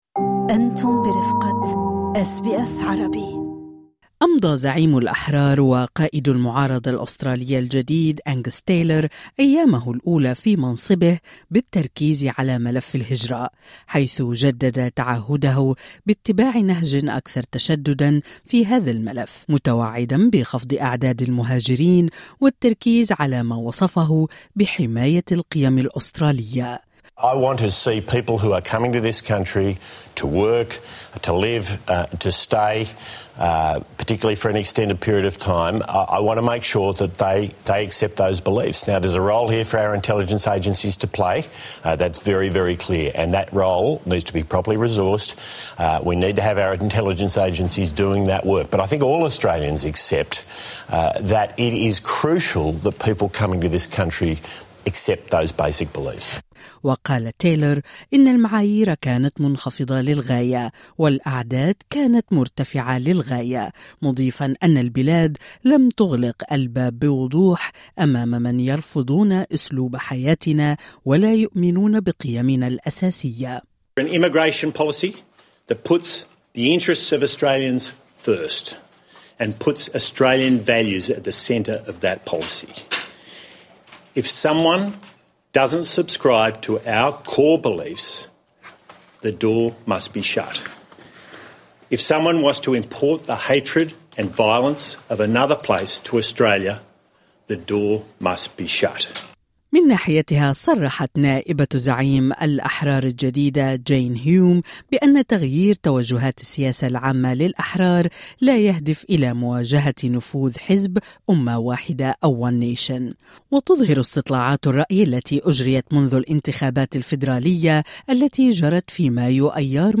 هل سيجبر صعود حزب امة واحدة في استطلاعات الرأي الاخيرة الاحرار على تقديم سياسات اكثر ميلا لليمين؟ استمعوا لهذا التقرير الذي يضم لقاءا